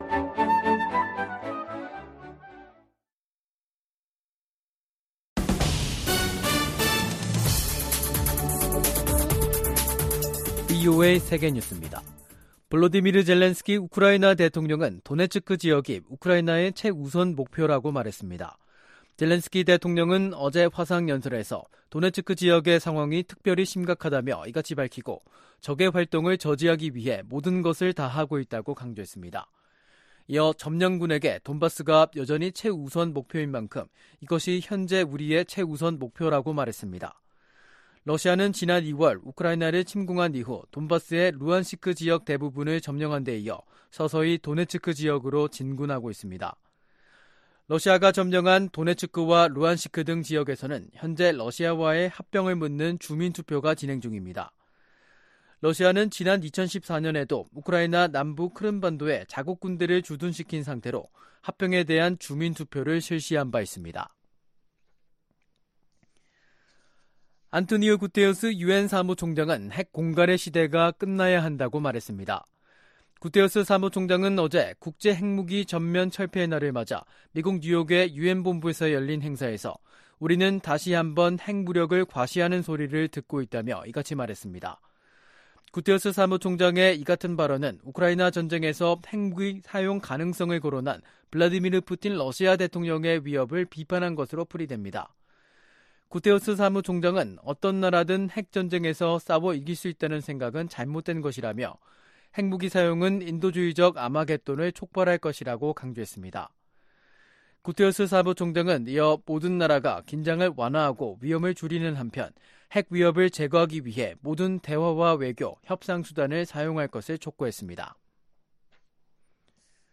VOA 한국어 간판 뉴스 프로그램 '뉴스 투데이', 2022년 9월 27일 2부 방송입니다. 미 국무부가 북한 정권의 어떤 도발도 한국과 일본에 대한 확고한 방어 의지를 꺾지 못할 것이라고 강조했습니다. 카멀라 해리스 미 부통령과 기시다 후미오 일본 총리가 회담에서 북한 정권의 탄도미사일 발사를 규탄했습니다. 북한이 핵 개발에 쓴 비용이 최대 16억 달러에 달한다는 분석 결과가 나왔습니다.